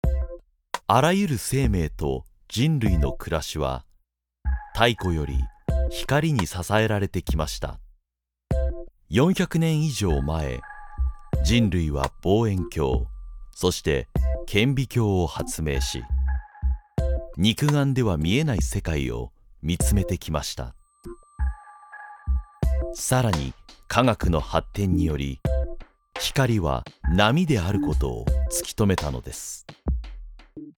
una voz de barítono enérgica y confiable
Muestras de voz nativa
Documentales